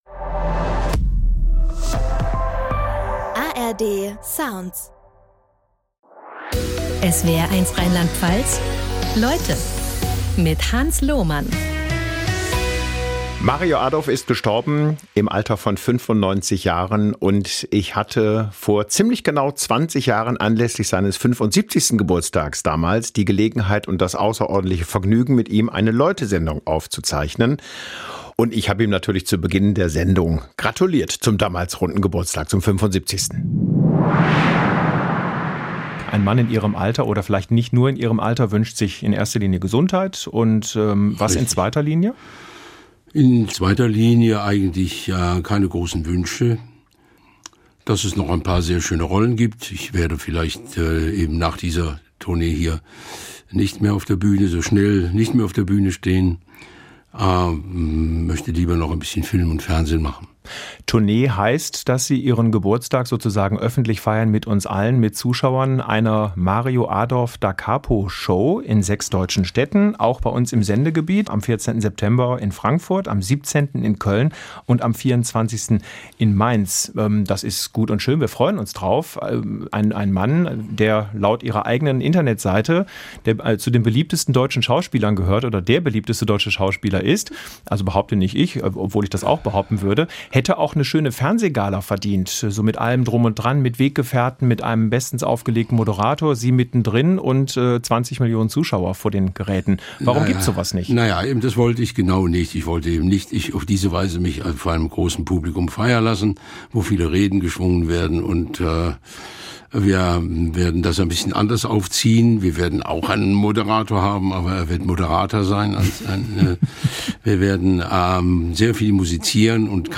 Vor etwas mehr als 20 Jahren war Mario Adorf zu Gast bei SWR1 Leute.